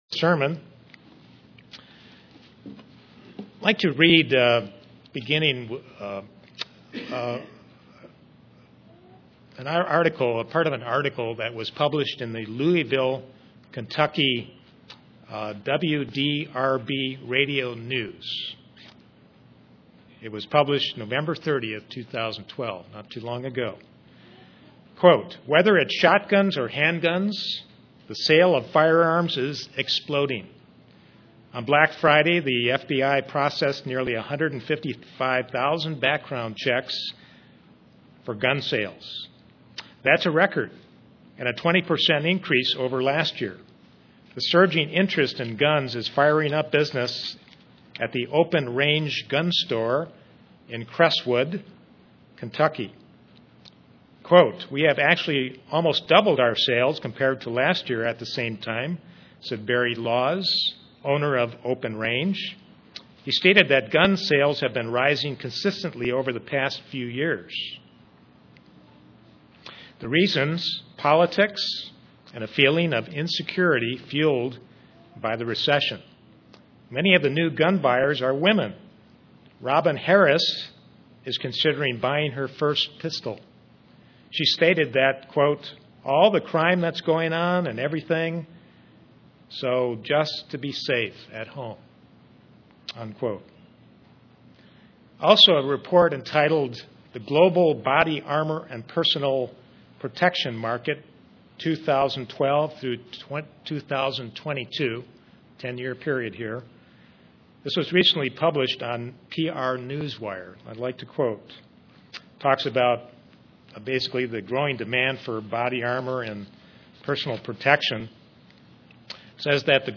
Given in Kingsport, TN
Print Based on Ephesians a Christian needs to put on the armor of God UCG Sermon Studying the bible?